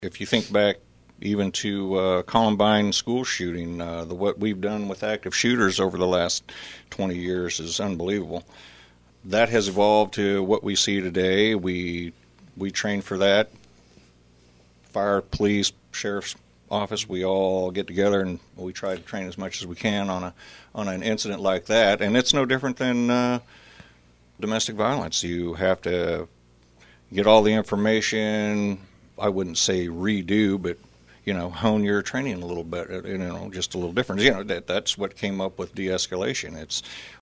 Emporia Police Chief Ed Owens, on the most recent installment of KVOE’s Monthly Q&A segment, says anytime an officer is injured or killed in the line of duty, it impacts all law enforcement agencies, including Emporia.